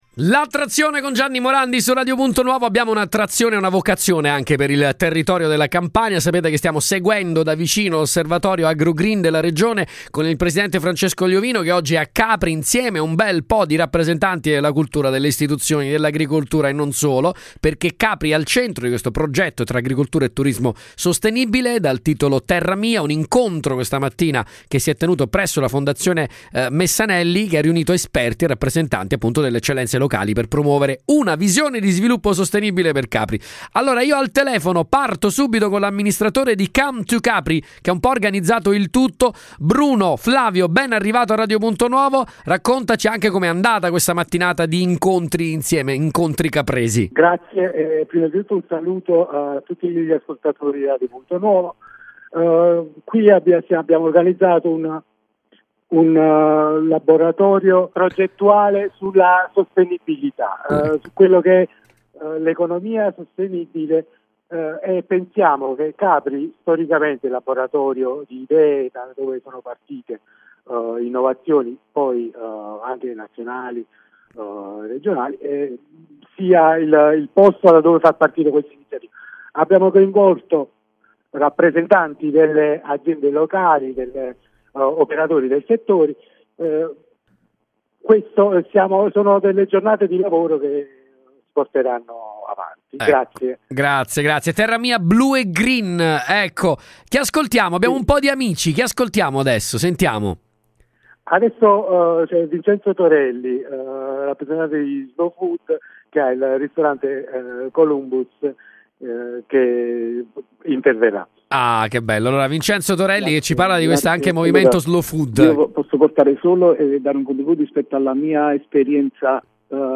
In diretta su Radio Punto Nuovo sono intervenuti i relatori.